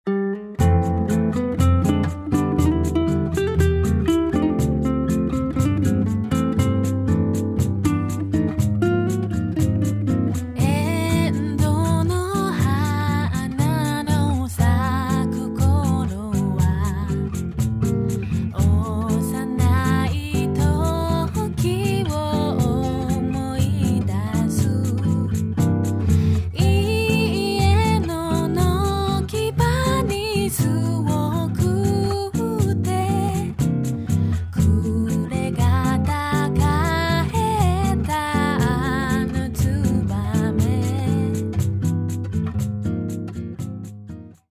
みんなの大好きな沖縄の名曲たちをボサノバで歌いました！